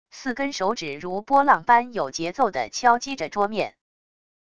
四根手指如波浪般有节奏地敲击着桌面wav音频